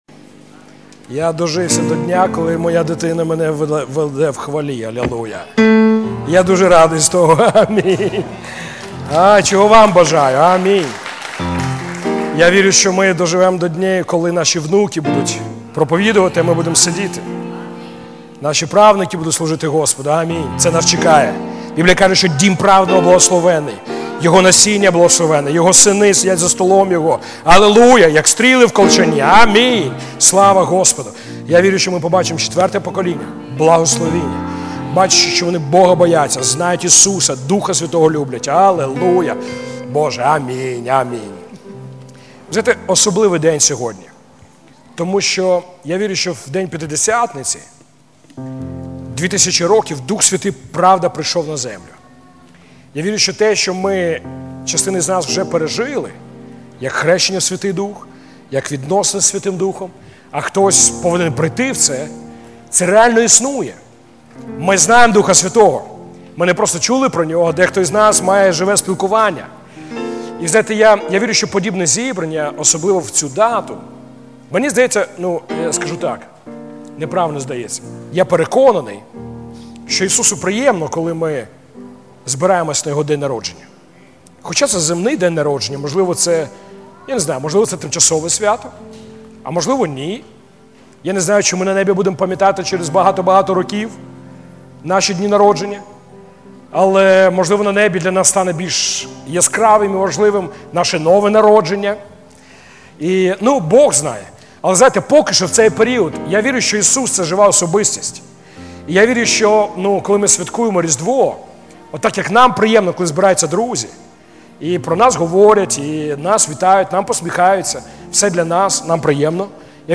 Аудио проповеди